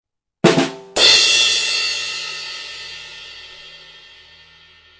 rimshot.mp3